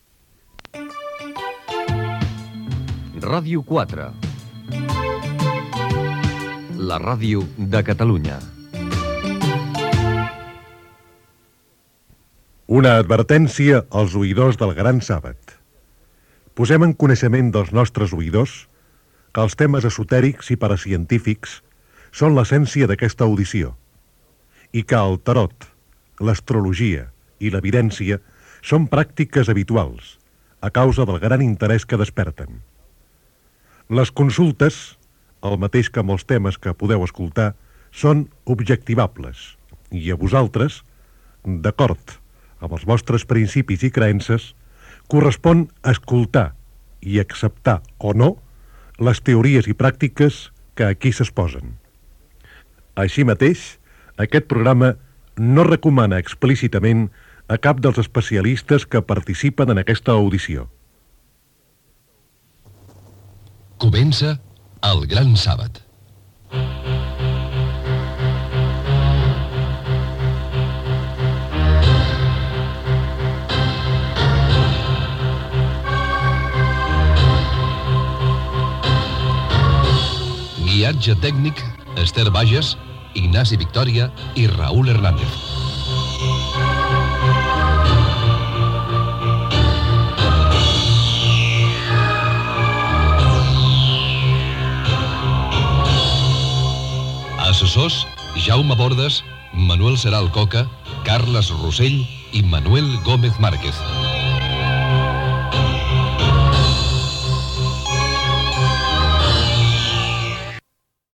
Indicatiu de la ràdio, advertència inicial del programa i careta amb els noms de l'equip
Divulgació